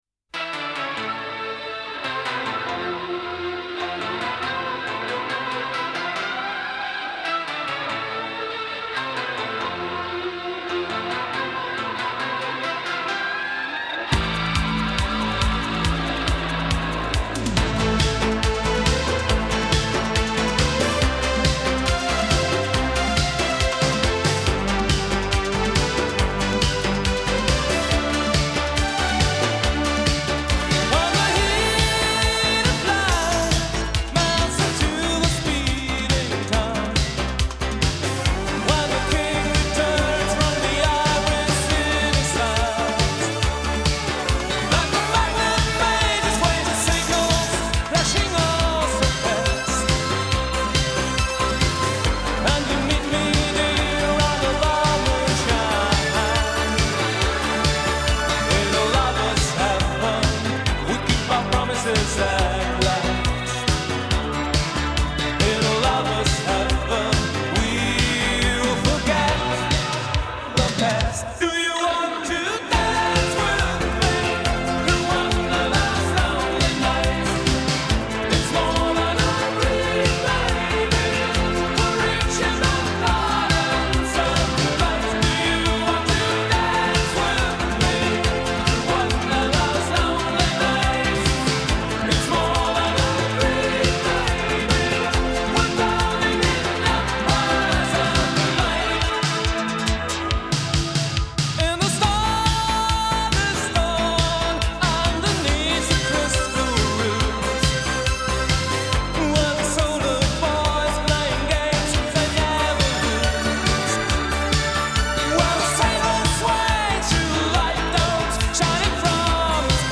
Галерея Music Disco 80